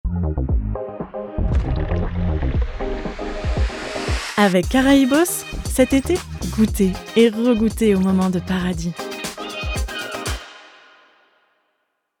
Voix Off Publicité : Caraïbos - Diffusion Réseaux Sociaux
Timbre médium, medium grave.